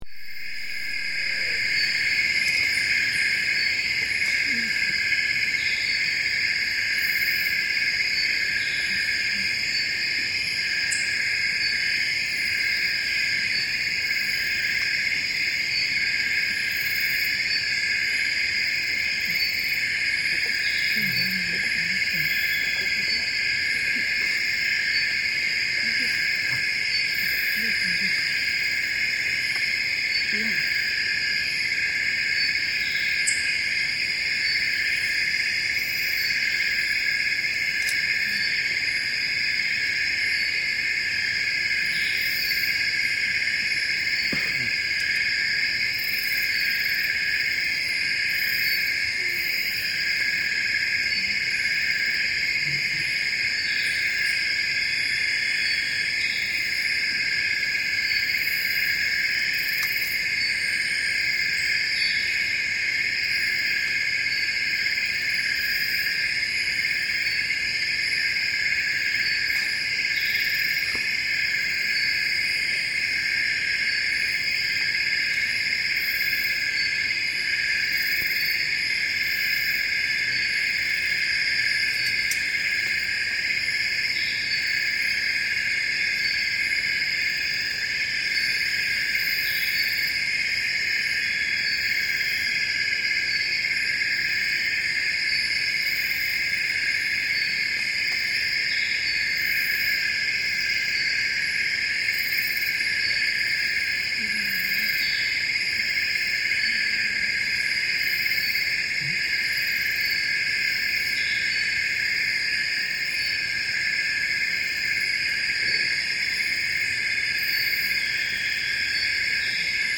Likimbi forest camp late at night